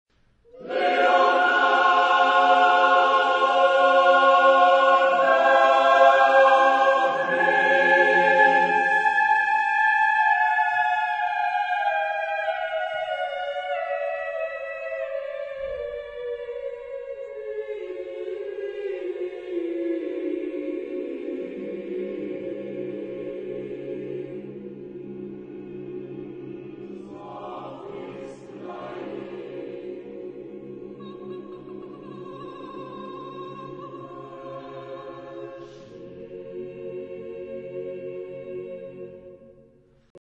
Type de choeur : SSATB  (5 voix mixtes )
Instrumentation : Percussions